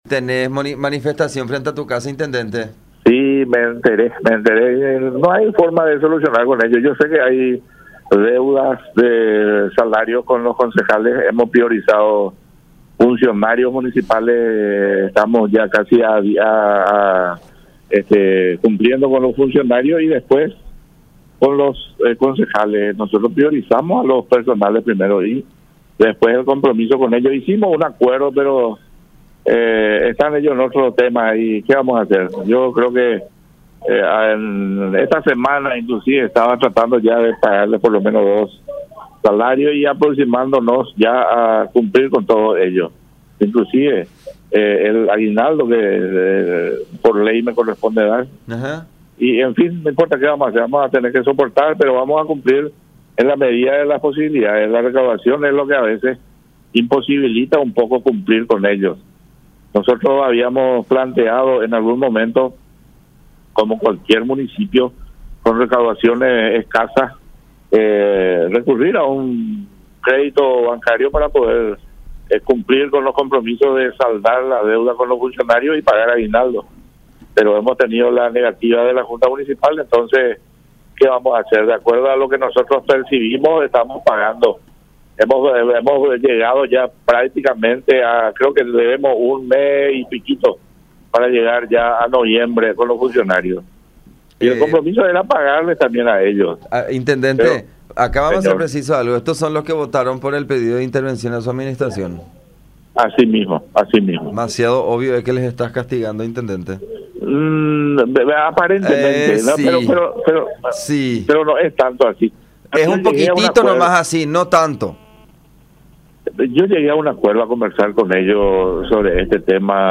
11-INTENDENTE-DE-LIMPIO-CARLOS-PALACIOS.mp3